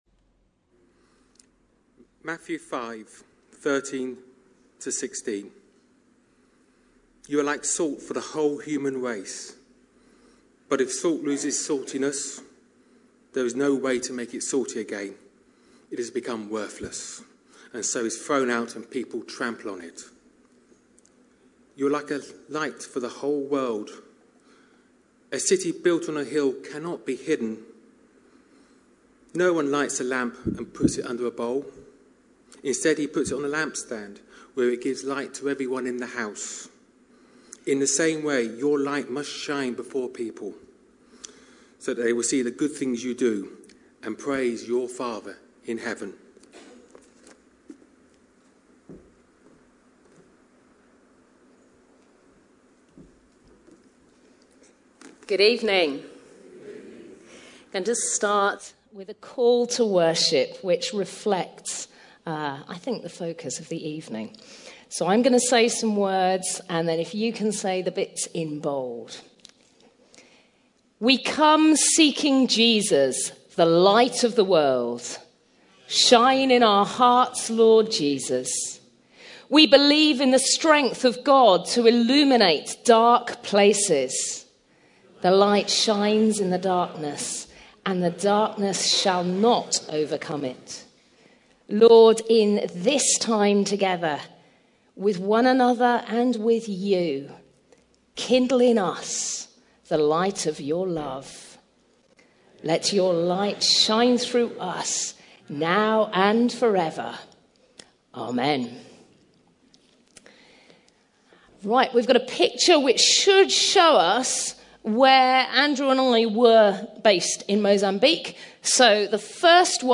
A sermon preached on 9th July, 2017.